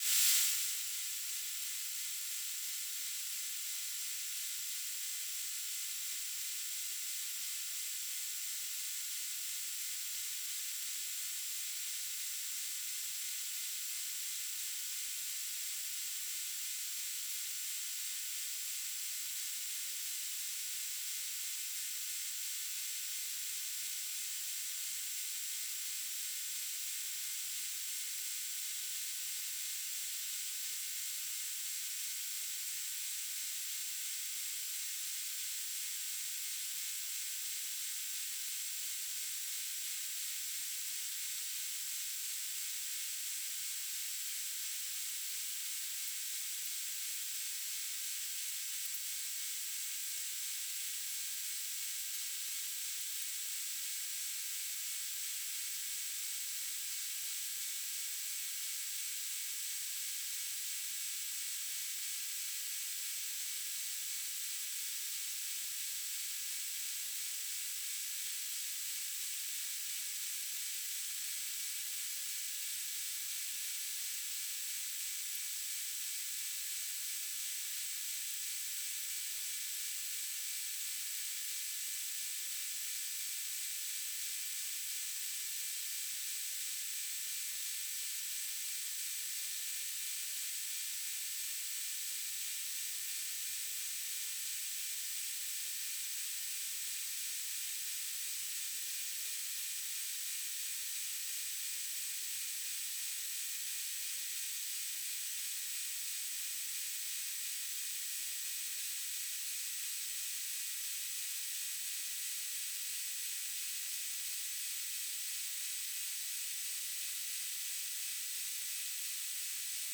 "transmitter_description": "Beacon",
"transmitter_mode": "BPSK",